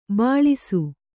♪ bāḷisu